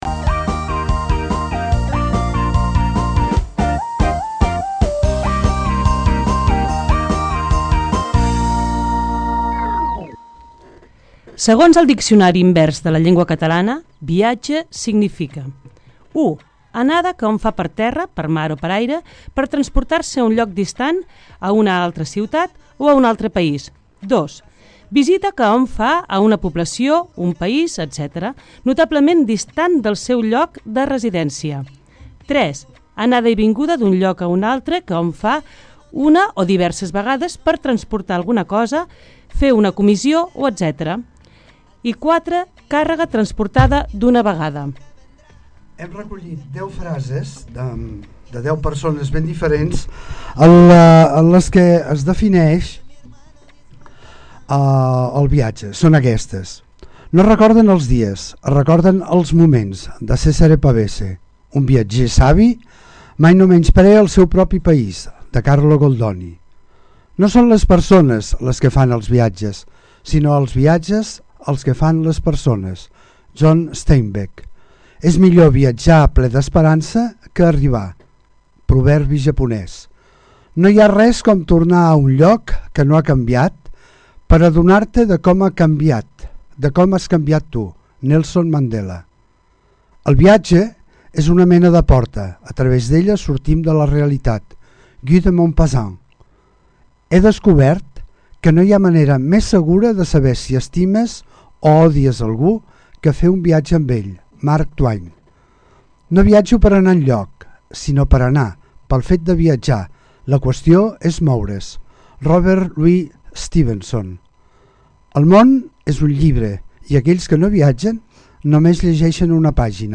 Aquesta setmana posem a debat la forma de viatjar. Des dels creuers fins a les tendes de campanya, passant també pels viatges amb vehicle particular, caravana o autocaravana.